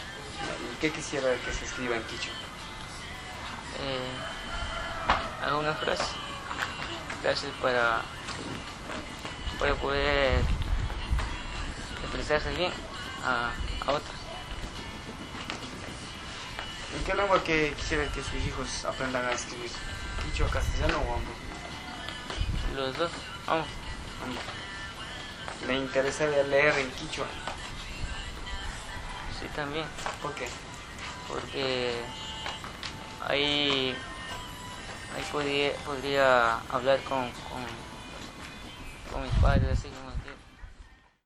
Entrevistas - San Cristóbal